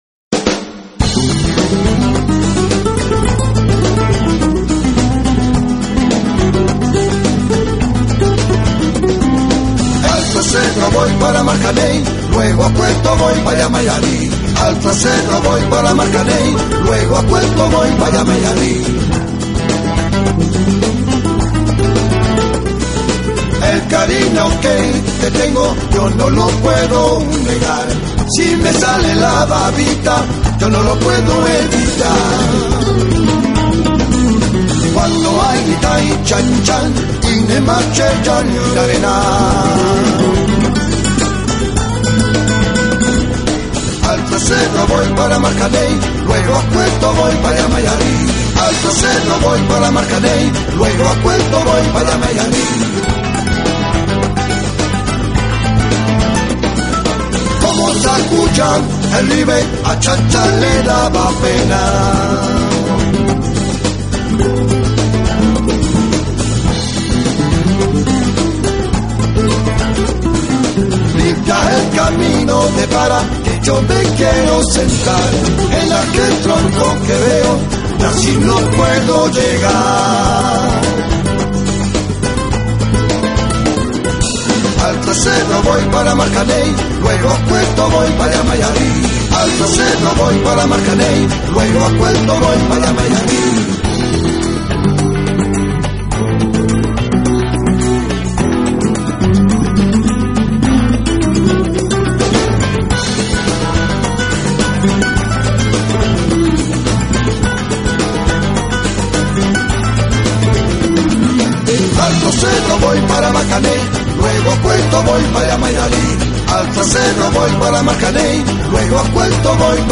并尝试运用丰富的管乐烘托融合了多样世界音乐元素的特出节奏。
是拉丁爵士与佛朗明哥的完美结合